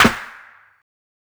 SNARE_CATCH_ME.wav